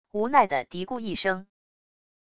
无奈的嘀咕一声wav音频